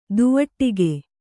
♪ duvaṭṭige